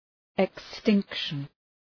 Προφορά
{ık’stıŋkʃən}